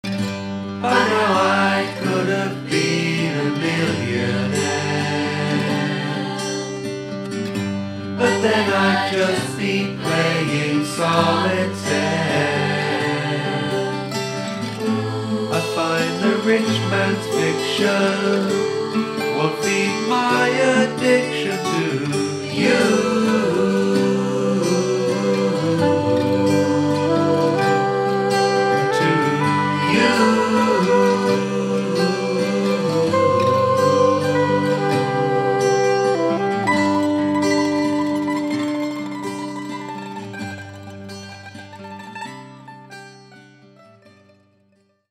Little Pink Studio, New Plymouth